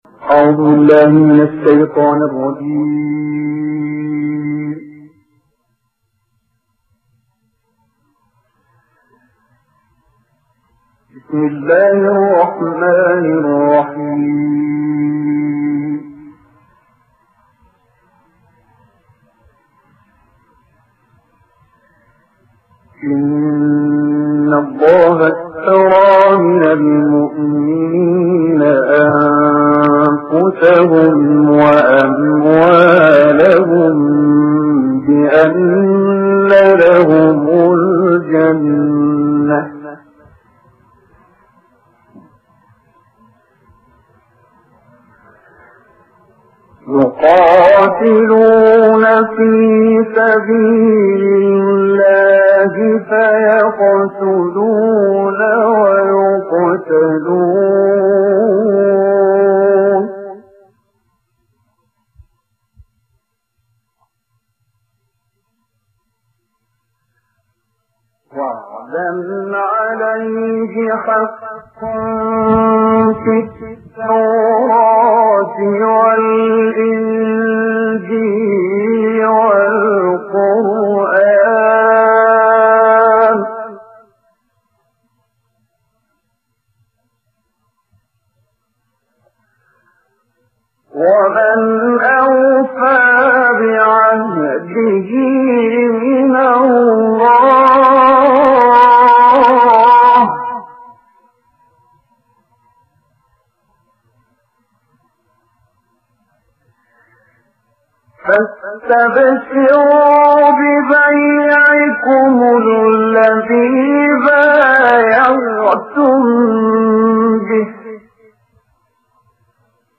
تلاوت شنیدنی